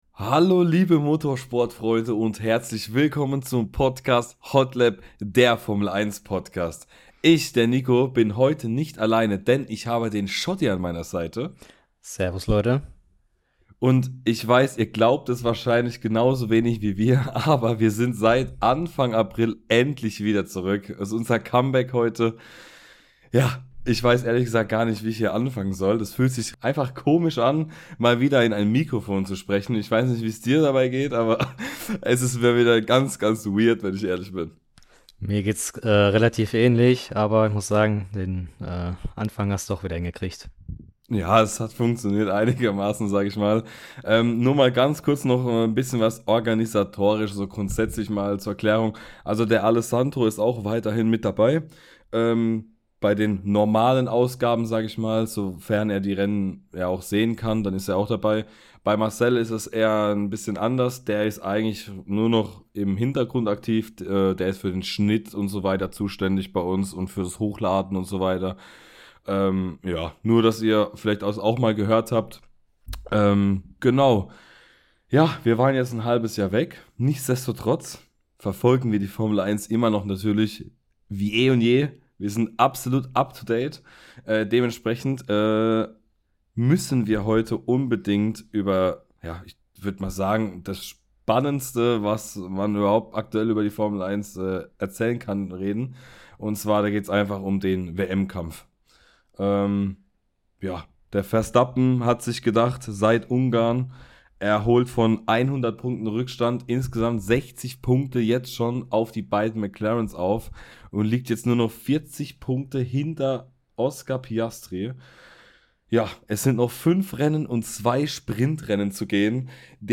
Wir sind drei F1 Fans, die nach jedem Rennen über alle Geschehnisse sprechen & diskutieren.